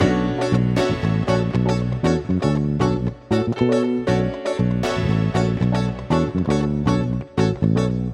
12 Backing PT1.wav